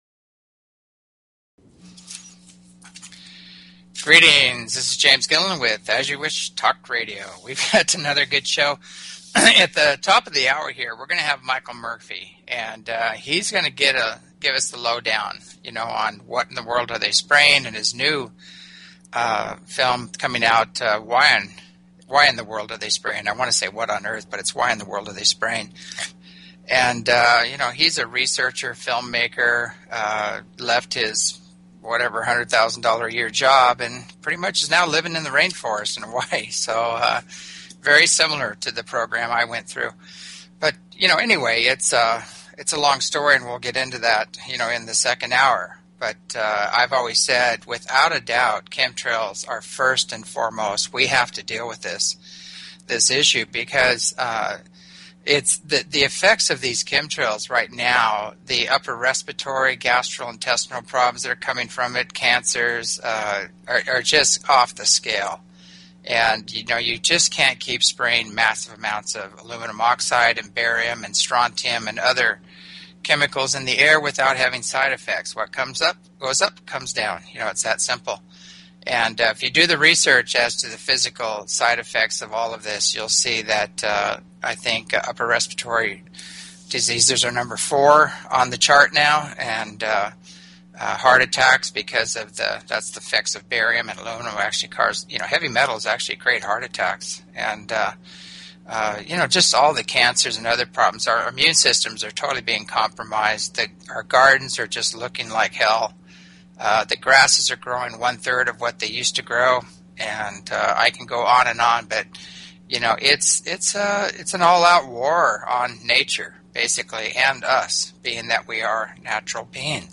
Talk Show Episode, Audio Podcast, As_You_Wish_Talk_Radio and Courtesy of BBS Radio on , show guests , about , categorized as
!st hour listener Call ins...